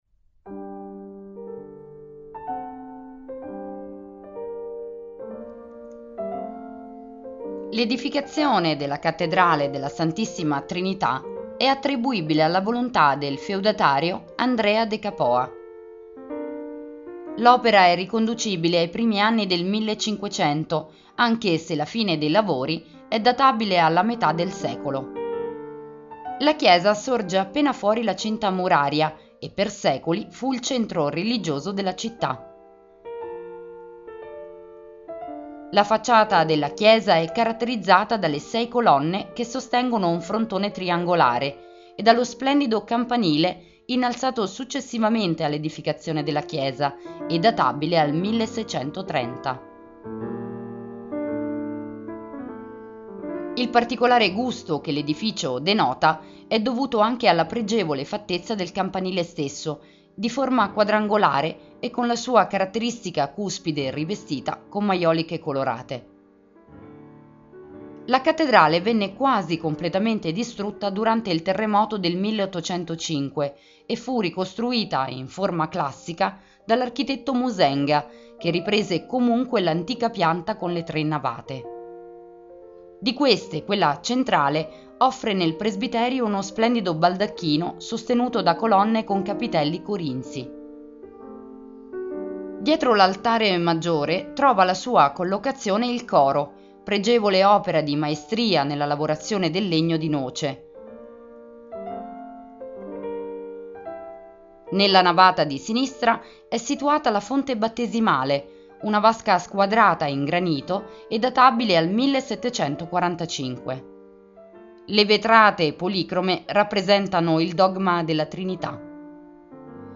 Audioguida Campobasso - Chiesa della Trinità - Audiocittà